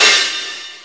MPC3 CRASH.wav